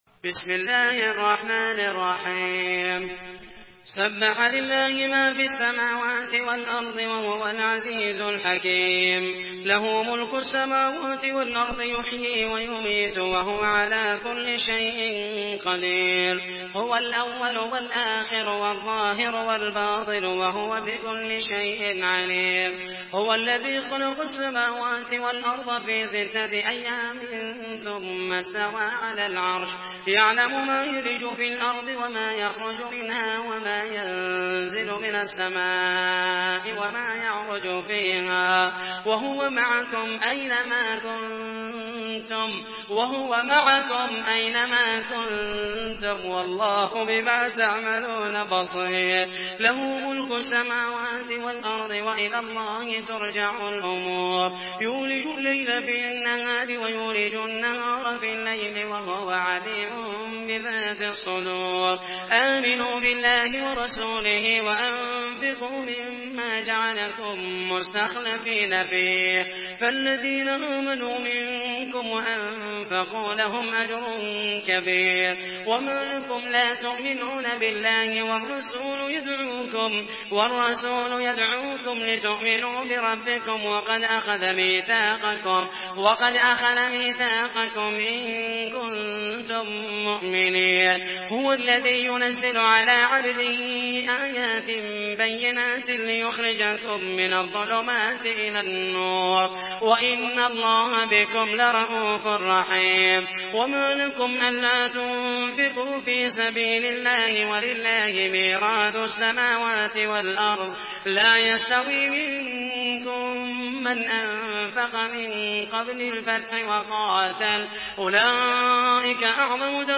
تحميل سورة الحديد بصوت محمد المحيسني
مرتل حفص عن عاصم